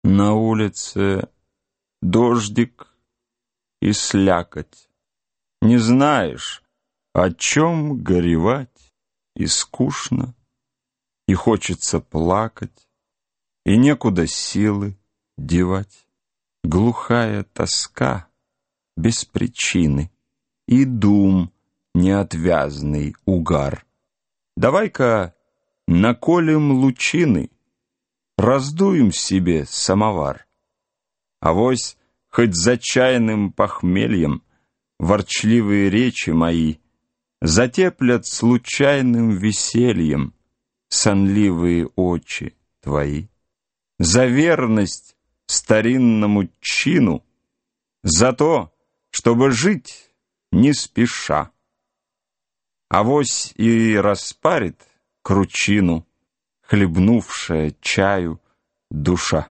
Аудиокнига Стихотворения | Библиотека аудиокниг